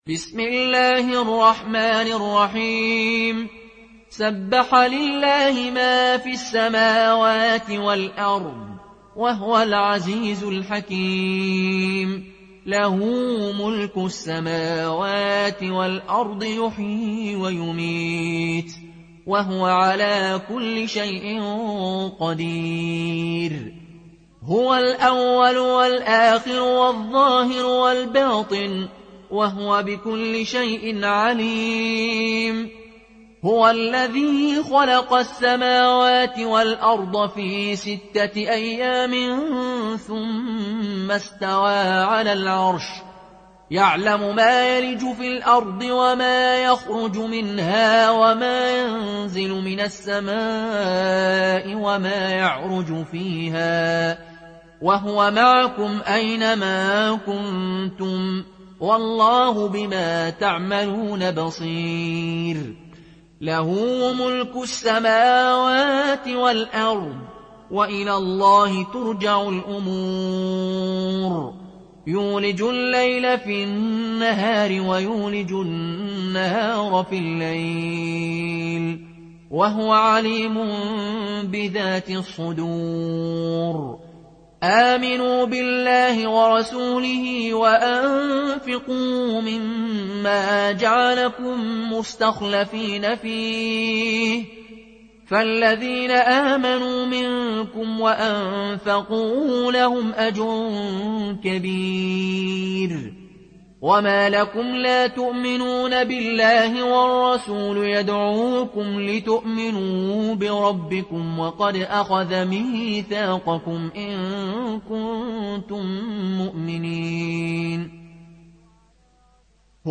قالون عن نافع